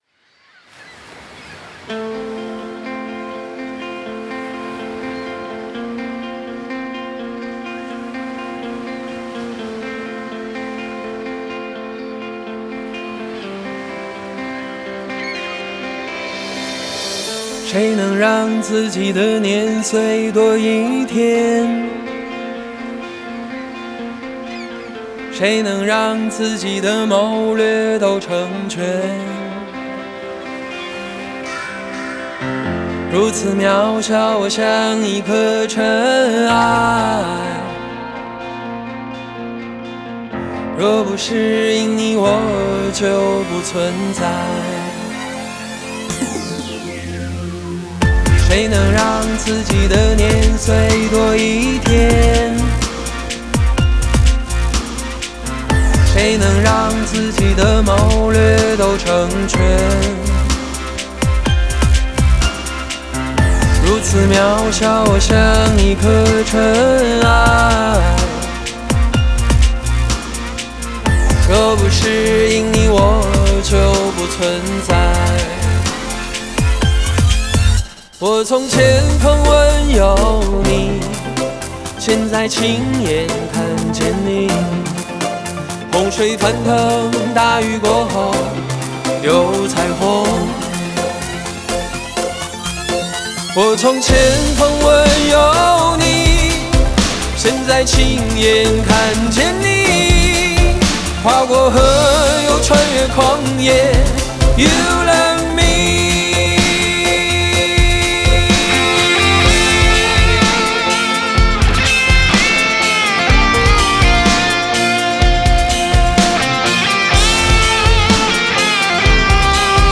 制作：Ekklesia国度12领导力 HAKA三层天祷告框架： 敬拜音乐：新的异象，新的方向 默想经文及宣告： 当进入第四步，也用 1189 经文默想的方式，来选择 被圣灵感动要默想或者宣告的经文，来为五执华人国 际团队，来为家庭，来为自己进行经文的场景中默想，祷告，宣告，突破！